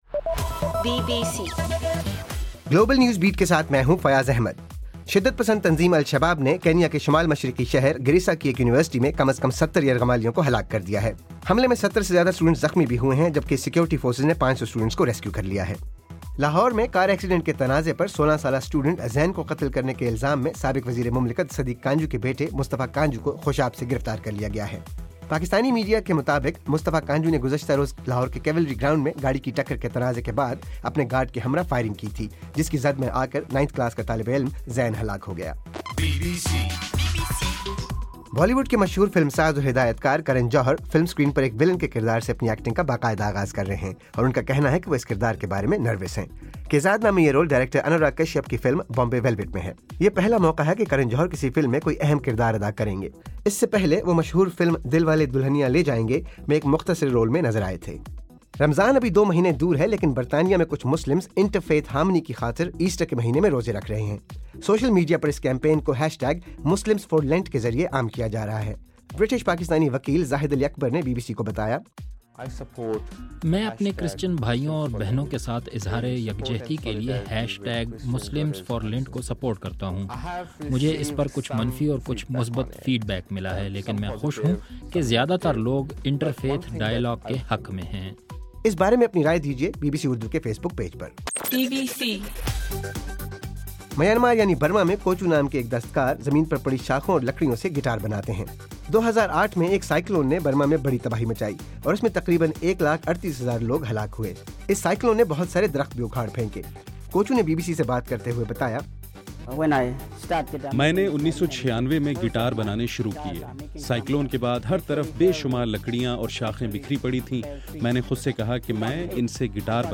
اپریل 2: رات 11 بجے کا گلوبل نیوز بیٹ بُلیٹن